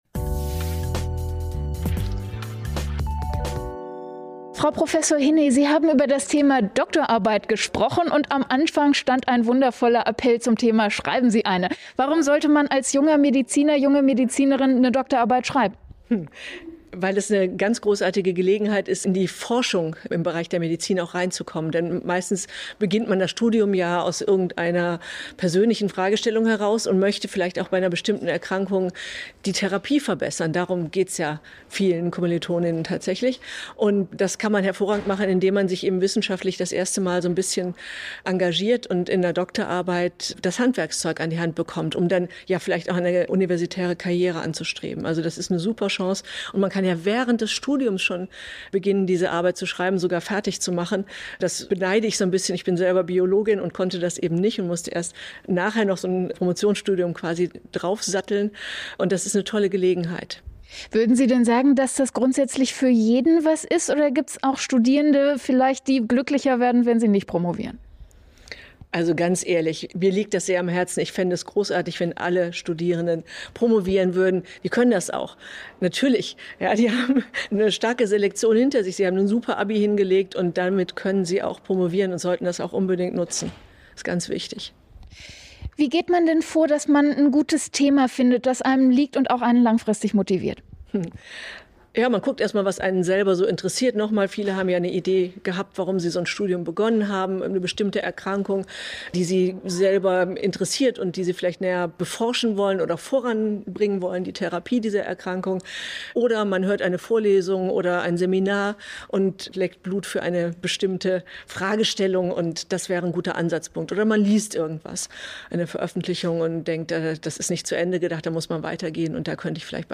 Interview
Im Interview am Rande des Operation Karriere-Kongresses in Essen am